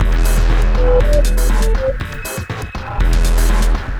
On The Move (Full) 120 BPM.wav